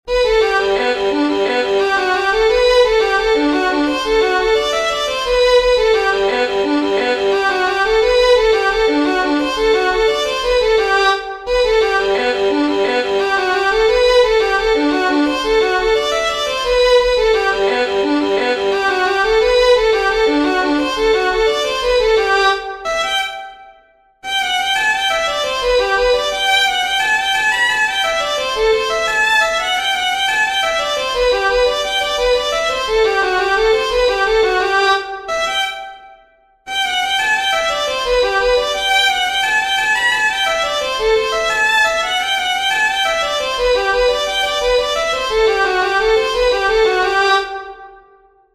PARTICIPACTION_REEL.mp3